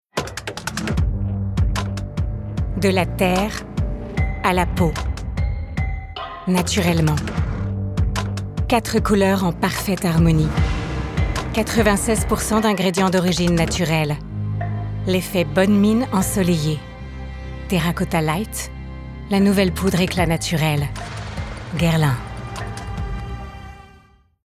Voix off
pub Guerlain